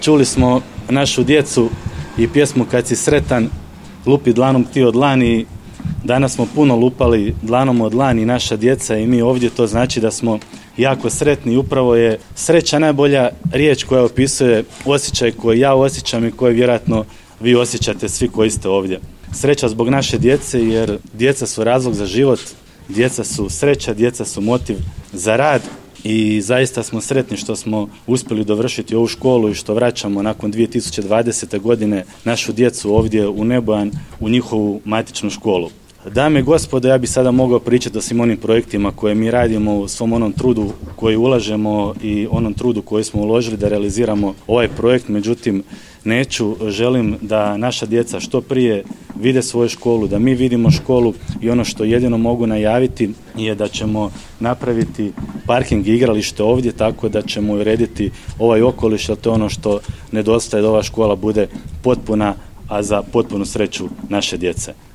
U petak, 28. srpnja 2023. godine, u Nebojanu je svečano otvorena novoizgrađena zgrada Područne škole Osnovne škole Ivan Goran Kovačić Gora.
Župan Ivan Celjak